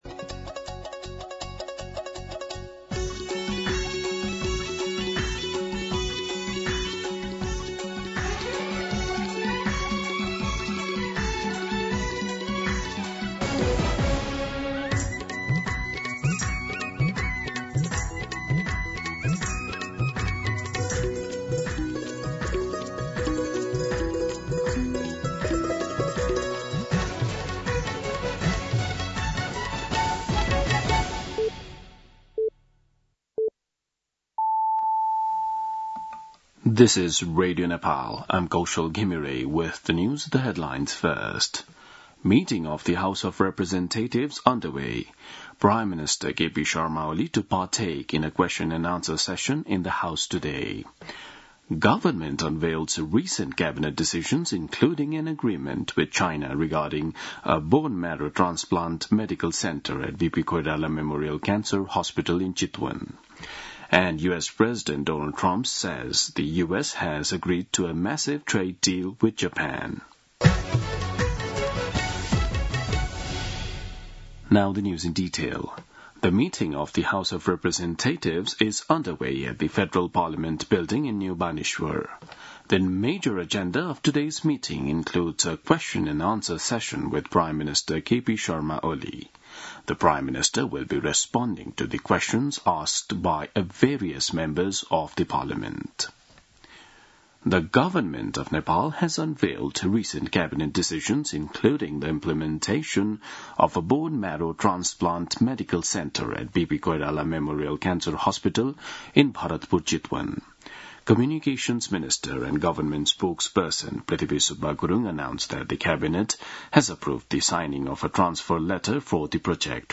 दिउँसो २ बजेको अङ्ग्रेजी समाचार : ७ साउन , २०८२